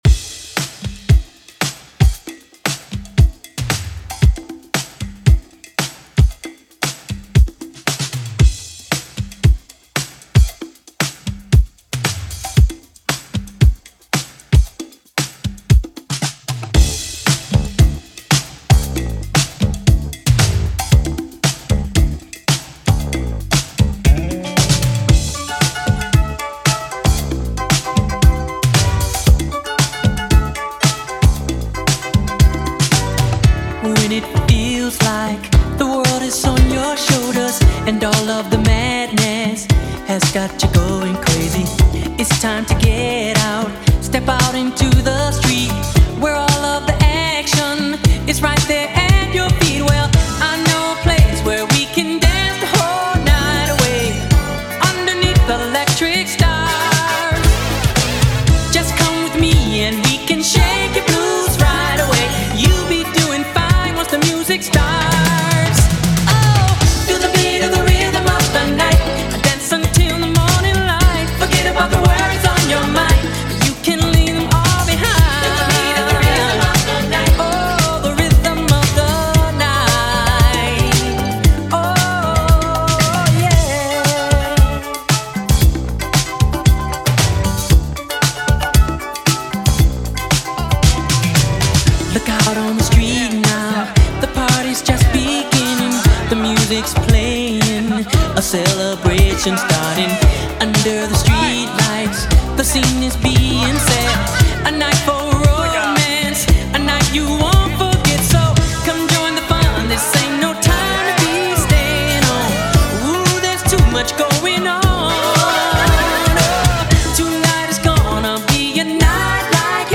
BPM: 115 Time